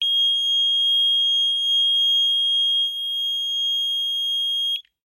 Звуки металлоискателя